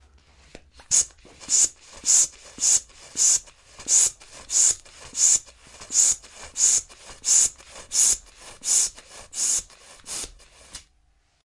充气气球
描述：一个长气球通过气球泵充气。用Reaper和RødeNT1a麦克风录制。
Tag: 膨胀 膨胀 压力 空气 泵送 球囊泵 气球 长气球 OWI